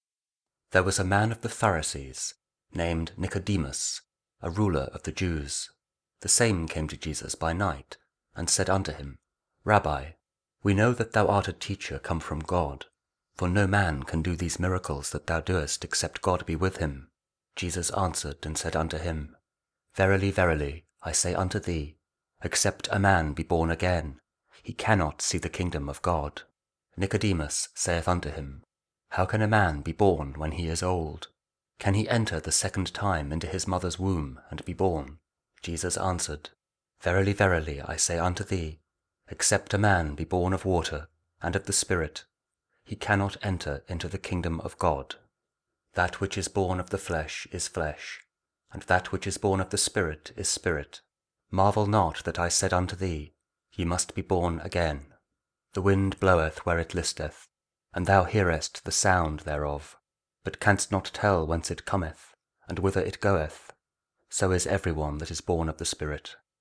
John 3: 1-8 Audio Bible KJV | King James Audio Bible | King James Version | Daily Verses